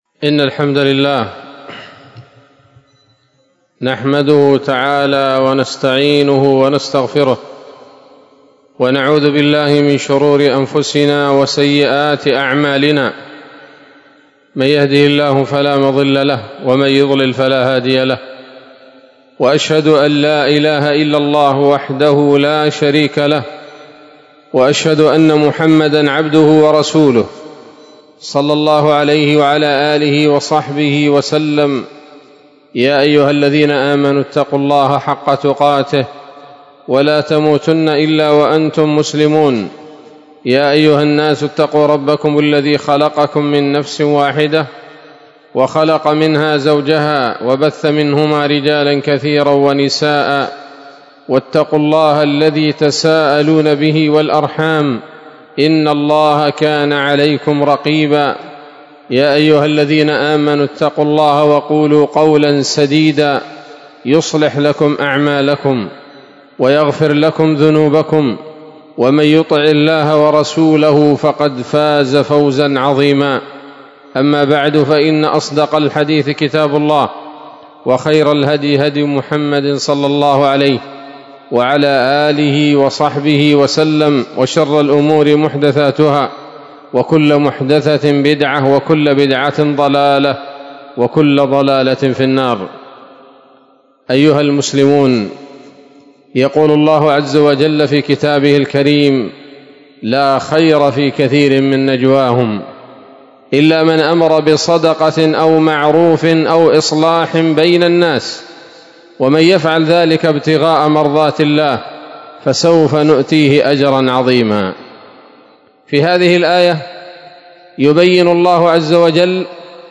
خطبة جمعة بعنوان: (( الفلاح في السعي بين الناس بالإصلاح )) 27 جمادى الأولى 1446 هـ، دار الحديث السلفية بصلاح الدين